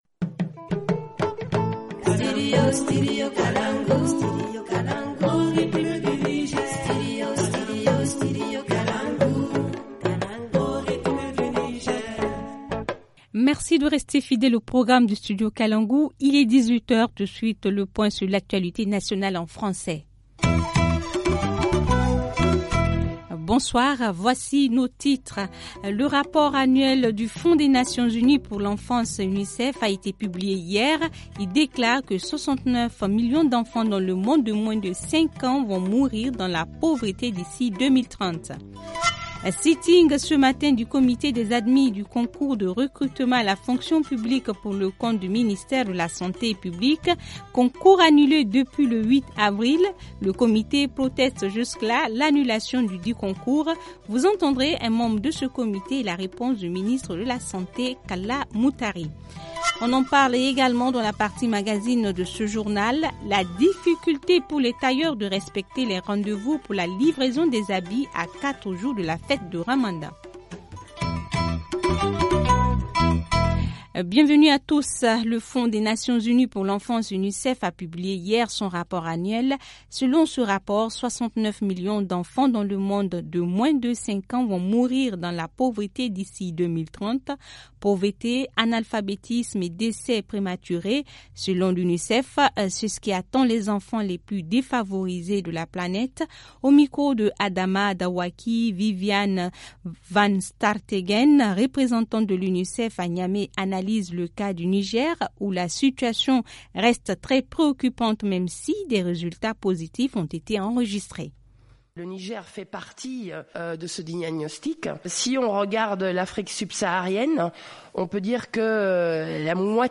Le comité proteste contre cette annulation. Vous entendrez un membre du comité des admis et la réponse du ministre de la santé publique, Kalla Moutari.